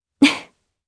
Chrisha-Vox_Happy1_jp.wav